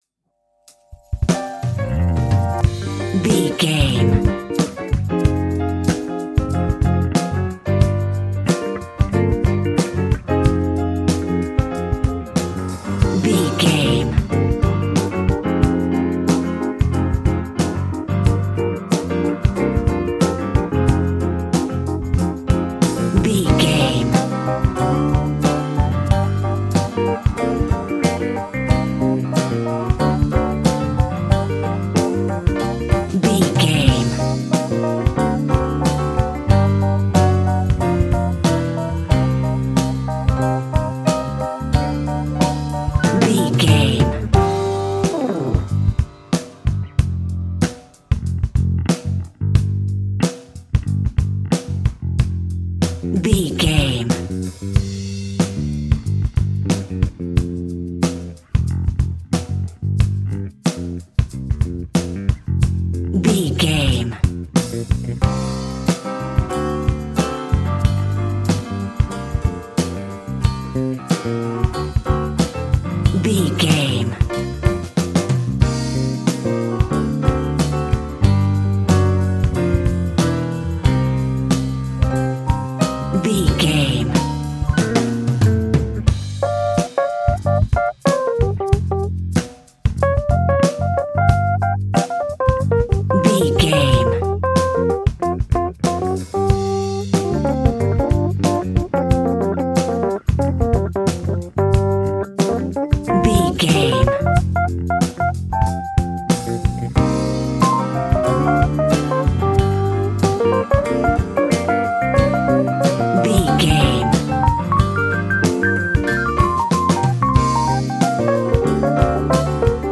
Uplifting
Ionian/Major
hip hop
instrumentals